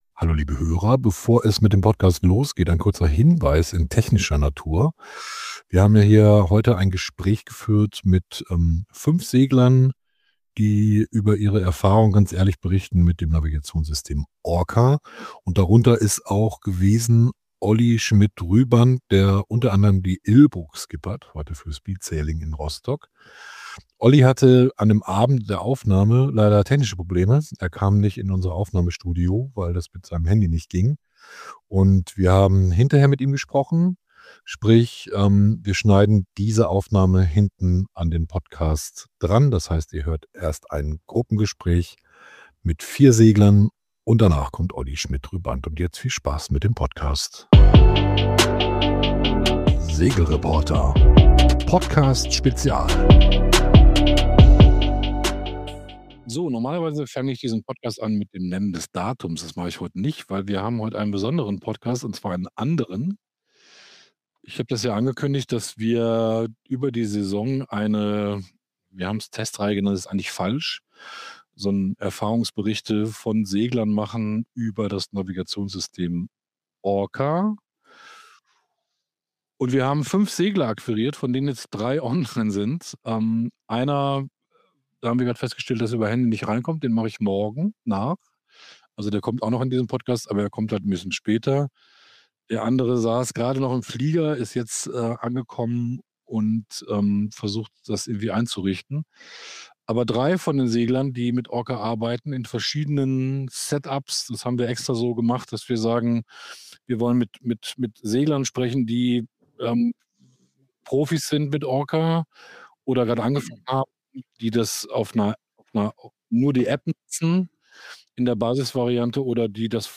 In dieser Folge stellen sich alle Segler vor.